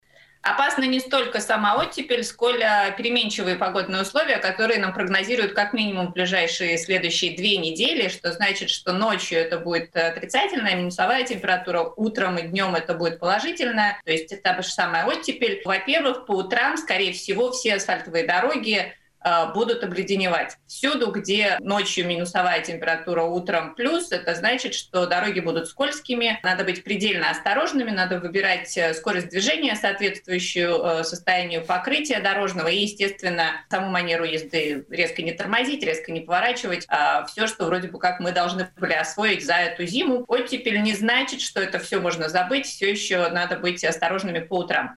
Кстати, обсуждали на радио Baltkom и состояние латвийских дорог в такой непростой период как оттепель.